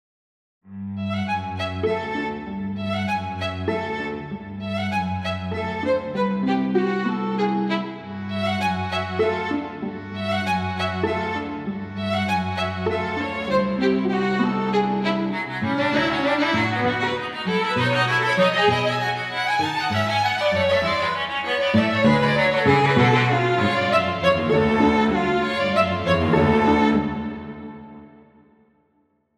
Divertimento and Variations for String Quartet
So this is a variations piece (once again) that I wrote based on my own original theme meant to explore cross-relations in a poly-tonal context.